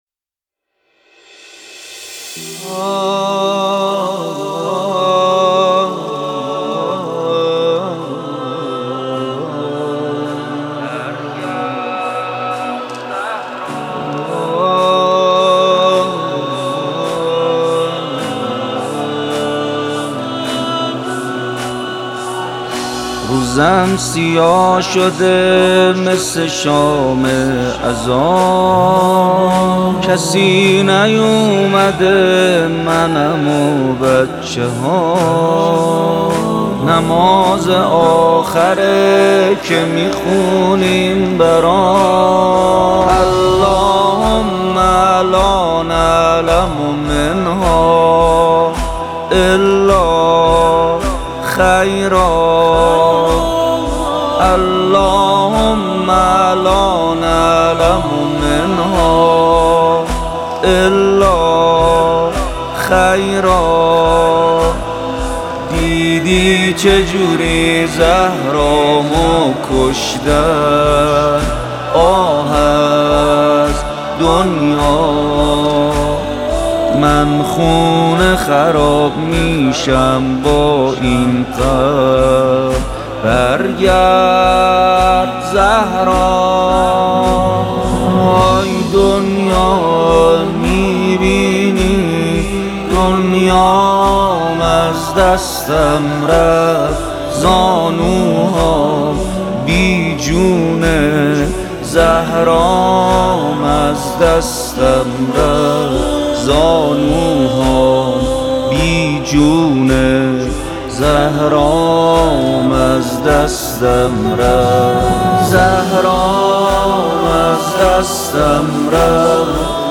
مداحی فاطمیه